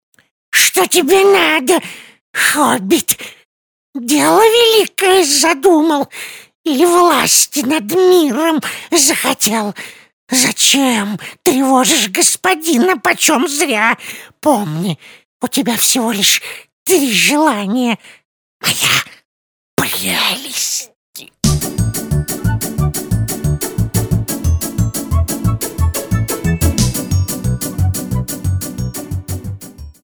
Parodiya_na_Goluma.mp3